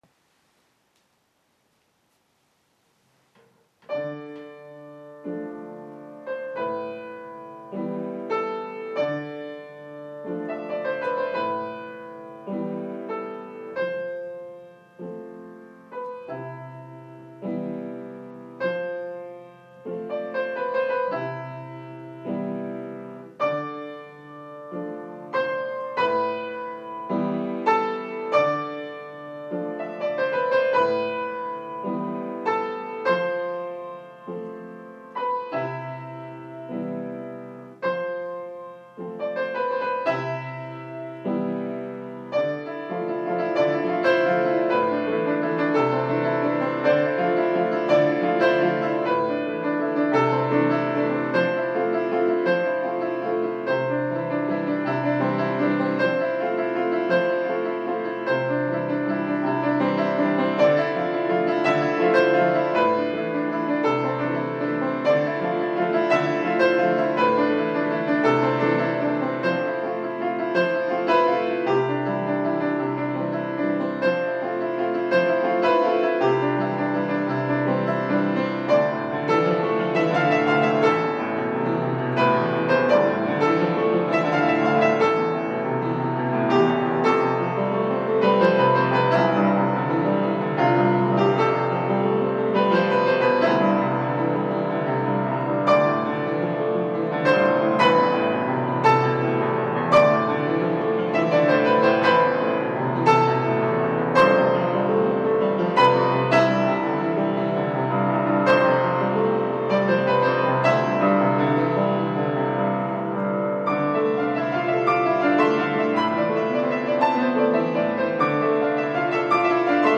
5 fautes de frappes et un décalage. Avant-fin trop lente.
2'16, 1,6 Mo, mp3 56 kbps (qualité de micro médiocre)